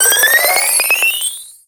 Cri de Prismillon dans Pokémon X et Y.